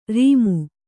♪ rīmu